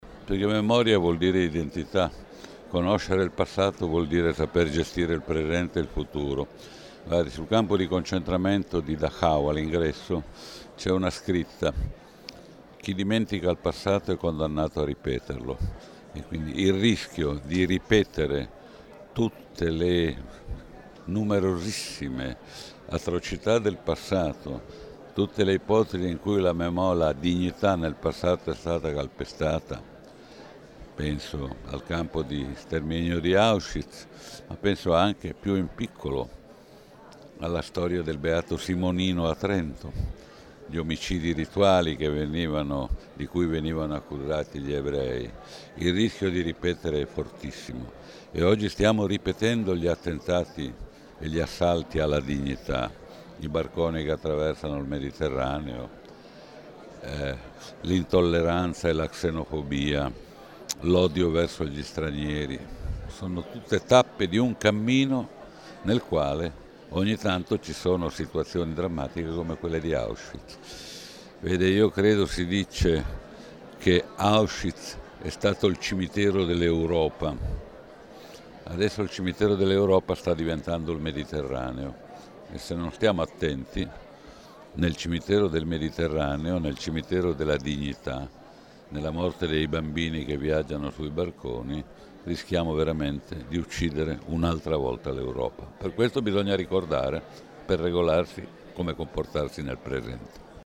Questa mattina, all’aula magna dell’Istituto tecnico tecnologico Buonarroti di Trento si è tenuto un incontro per gli studenti trentini con Giovanni Maria Flick.